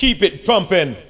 VOX SHORTS-1 0019.wav